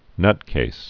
(nŭtkās)